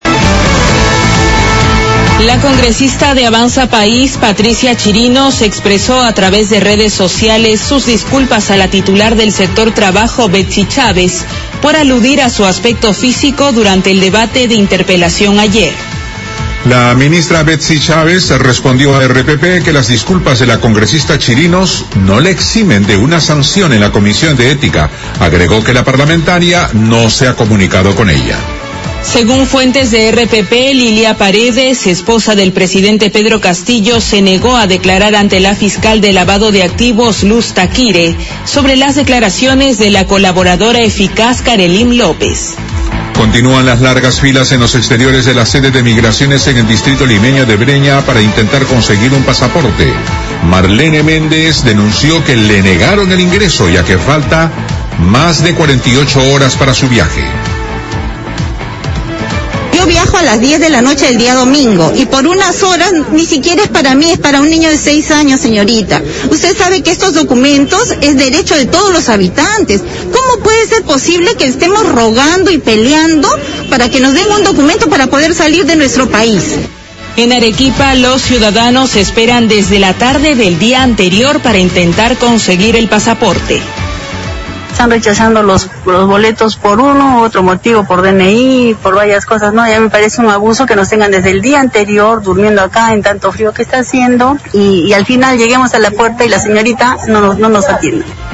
Gran resumen de la hora